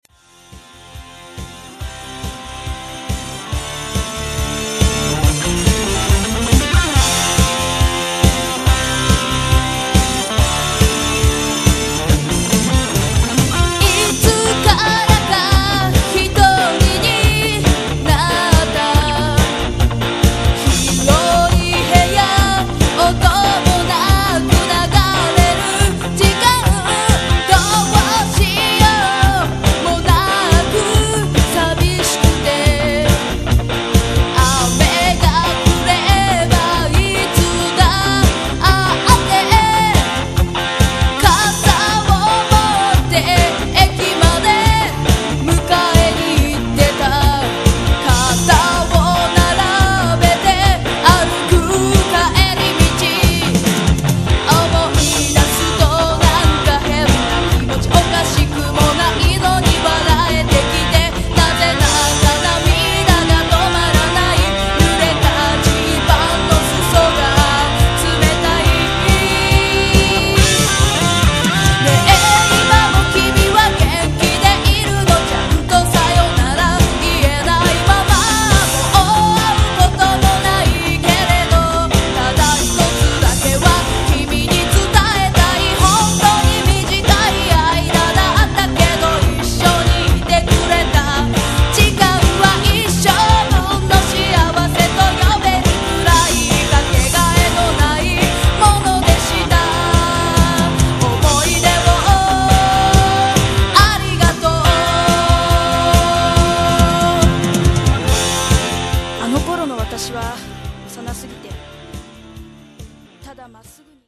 女性シンガー